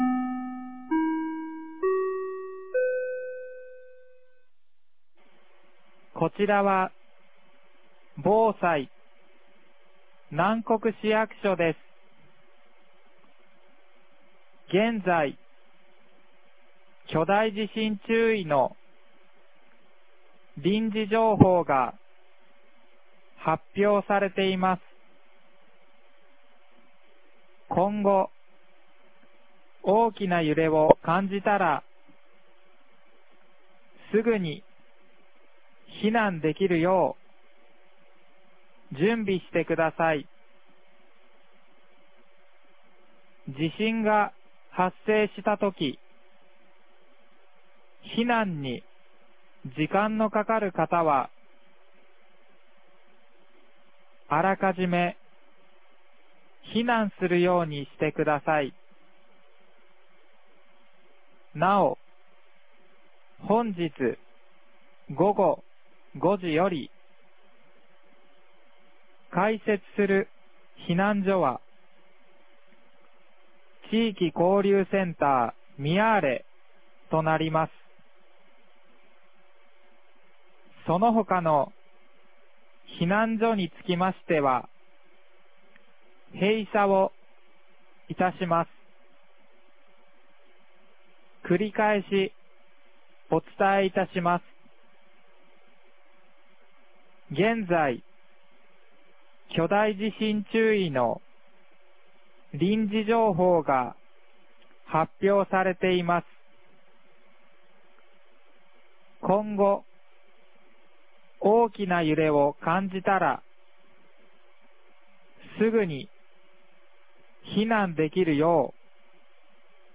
2024年08月10日 17時03分に、南国市より放送がありました。